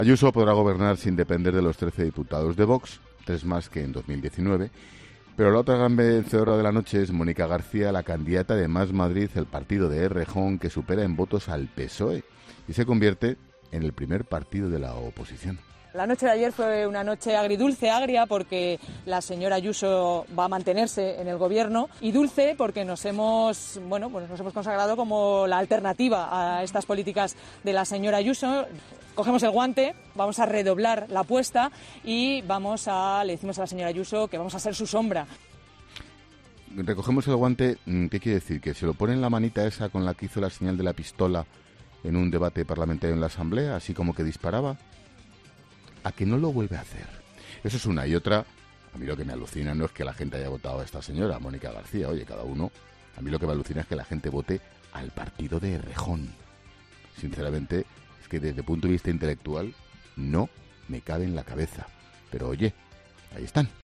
El presentador de 'La Linterna' valora a la nueva líder de la oposición en la capital
Ángel Expósito no ha querido dejar de opinar sobre el triunfo de esta formación en la jornada poselectoral, escuchando algunas declaraciones de la nueva líder de la oposición castiza para reflexionar después sobre ellas en 'La Linterna'.